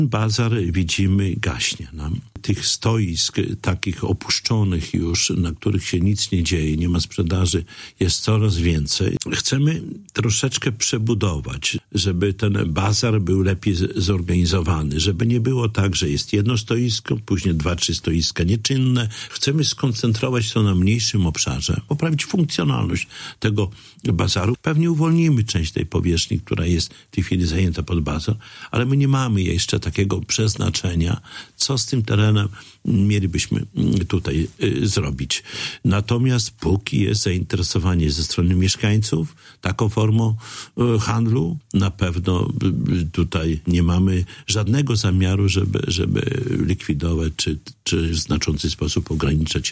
– Bazar gaśnie – mówi Czesław Renkiewicz, prezydent Suwałk. W związku z tym samorząd chce skoncentrować handel na mniejszym terenie, aby był lepiej zorganizowany. O planach dotyczących bazaru prezydent mówił w ostatniej „Audycji z Ratusza”.